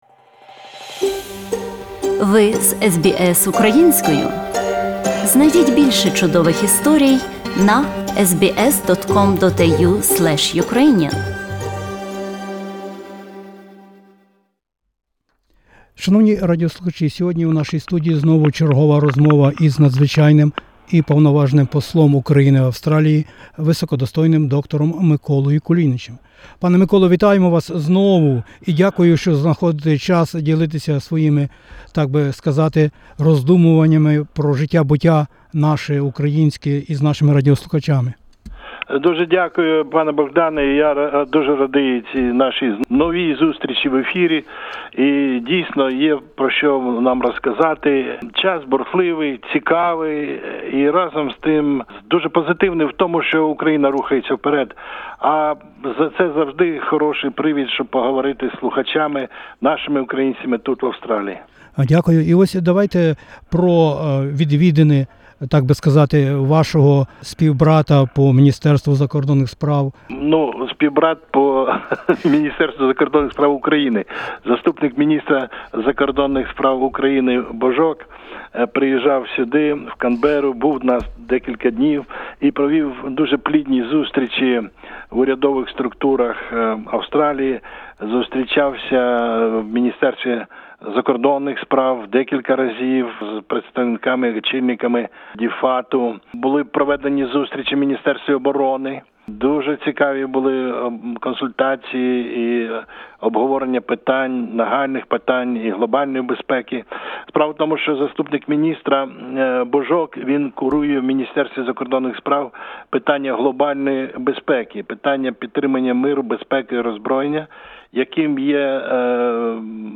розмовляє на хвилях SBS Radio із Надзвичайним і Повноважним Послом України в Австралії доктором Миколою Кулінічем. Ми, зокрема, ведемо мову про нещодавній робочиq візит до Австралії Заступникf міністра закордонних справ України Єгорf Божка.